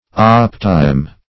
Search Result for " optime" : The Collaborative International Dictionary of English v.0.48: Optime \Op"ti*me\, n. [L., adv. fr. optimus the best.] One of those who stand in the second rank of honors, immediately after the wranglers, in the University of Cambridge, England.